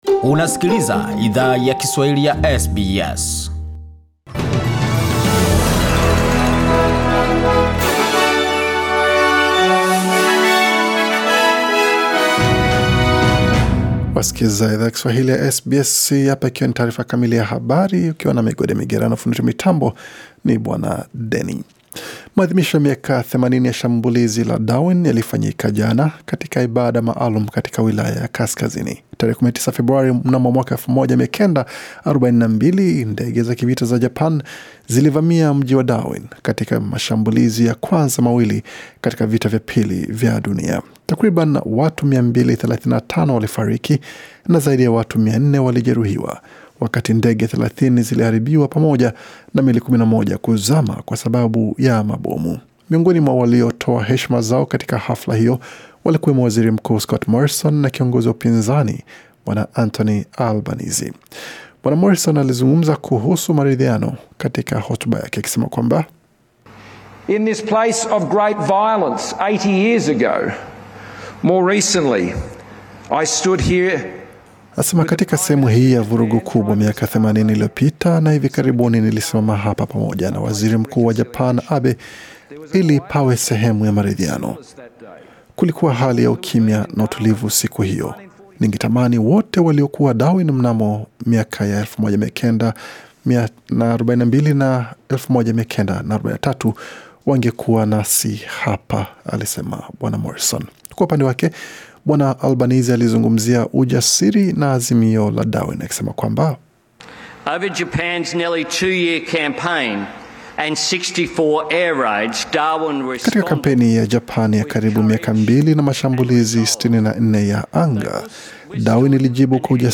Taarifa ya Habari 20 Februari 2022